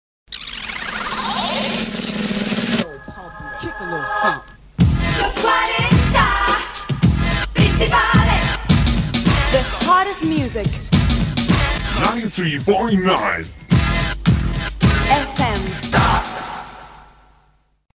Indicatiu de l'emissora en anglès.
FM